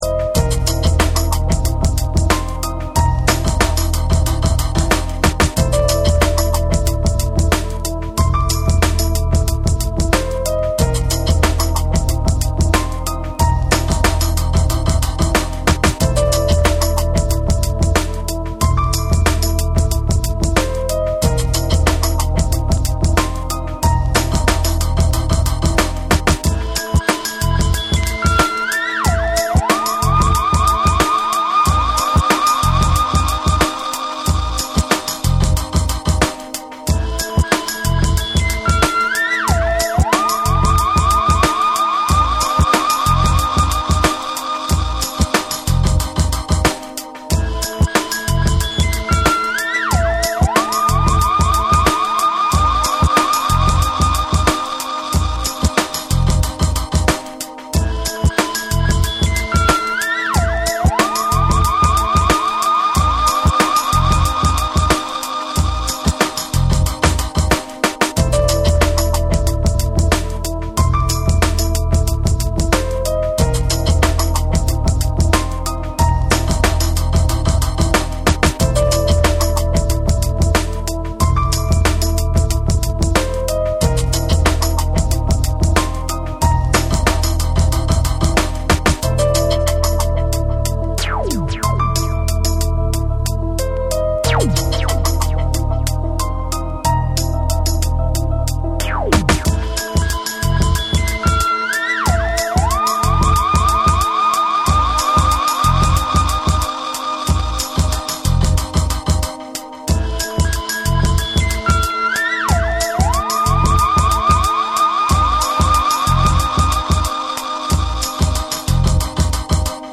BREAKBEATS / ORGANIC GROOVE